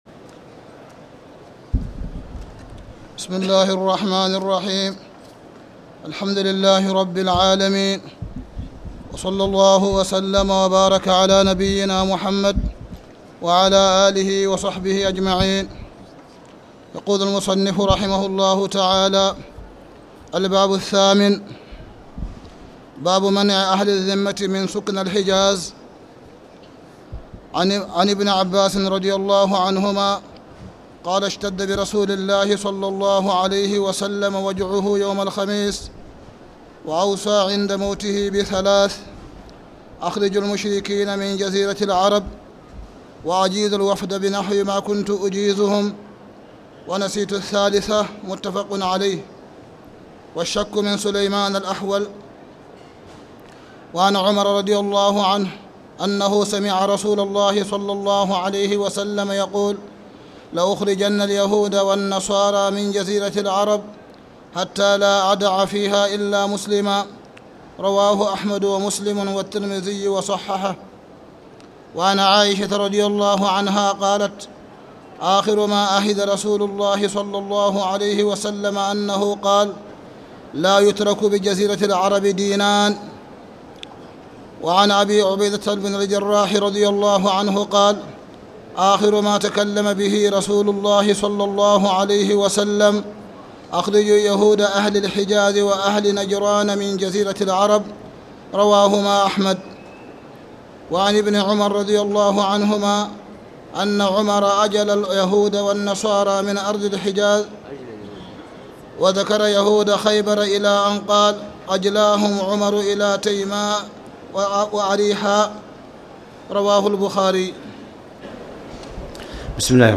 تاريخ النشر ٢ رمضان ١٤٣٨ هـ المكان: المسجد الحرام الشيخ: معالي الشيخ أ.د. صالح بن عبدالله بن حميد معالي الشيخ أ.د. صالح بن عبدالله بن حميد باب منع أهل الذمة من سُكنى الحجاز